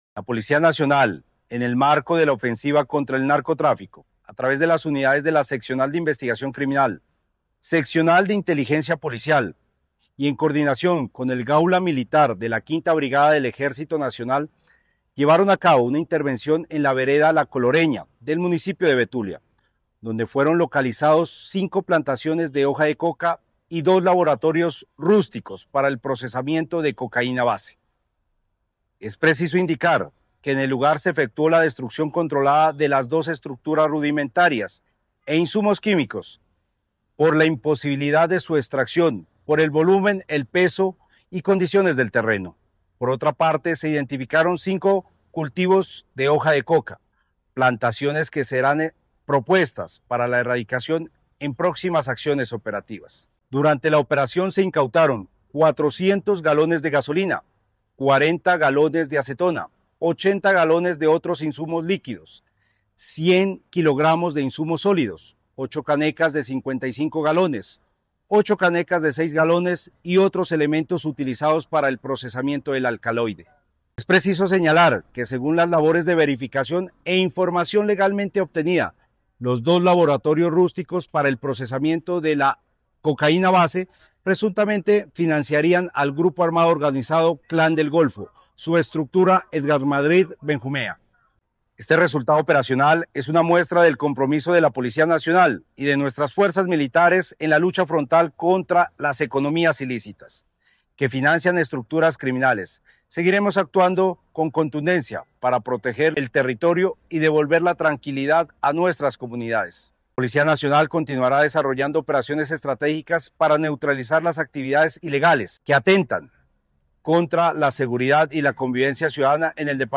Coronel Carlos Efrén Fuelagán, comandante del Departamento de Policía Santander